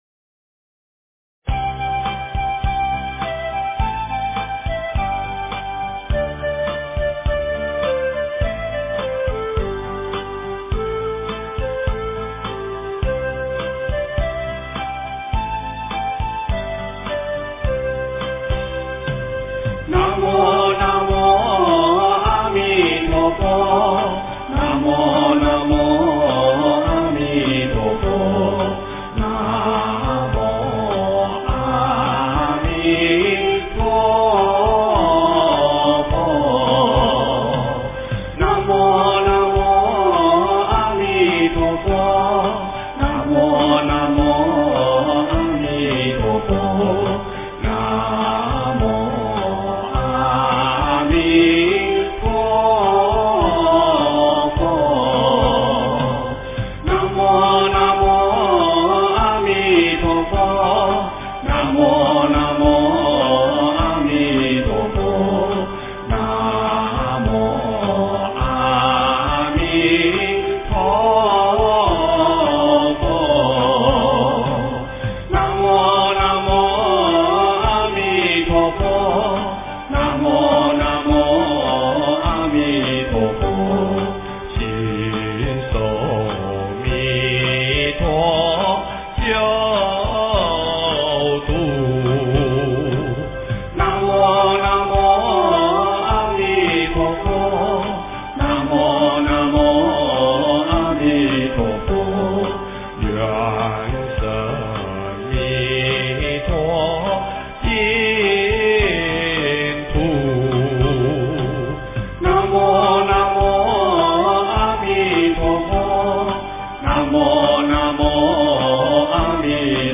经忏
佛音 经忏 佛教音乐 返回列表 上一篇： 晨钟偈--圆光佛学院众法师 下一篇： 晚课--僧团 相关文章 《妙法莲华经》随喜功德品第十八--佚名 《妙法莲华经》随喜功德品第十八--佚名...